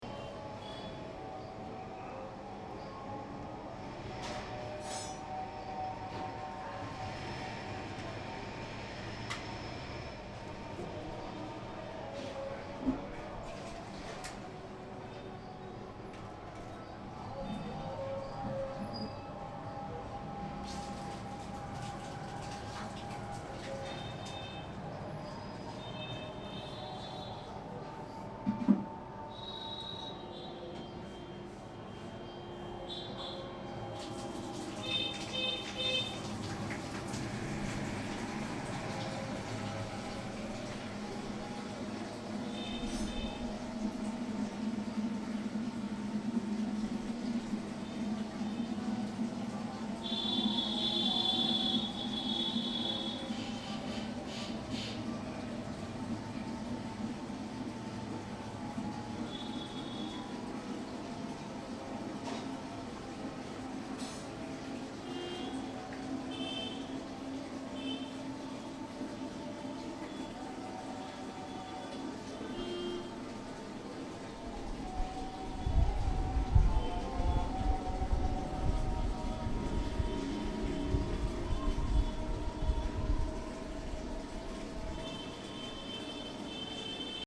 jodhpur_ambiance.mp3